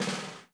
T5LV  BUZZ.wav